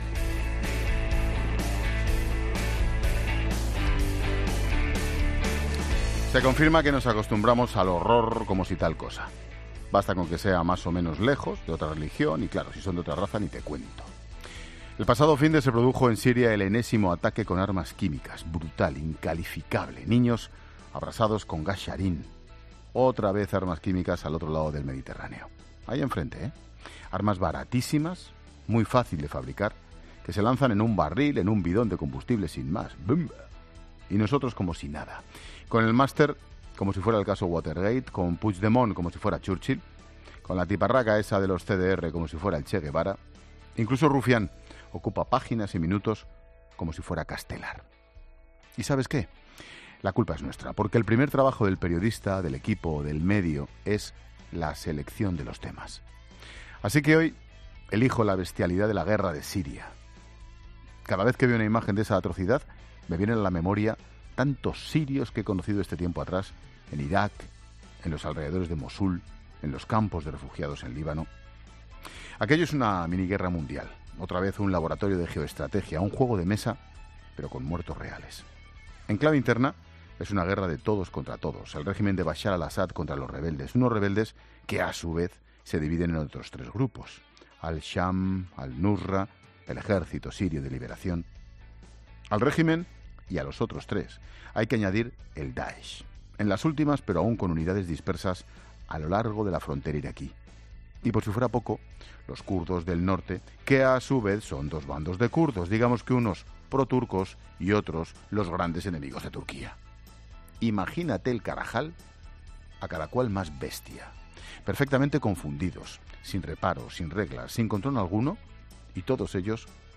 Monólogo de Expósito
El comentario de Ángel Expósito sobre la guerra en Siria.